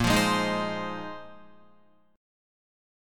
A#9sus4 chord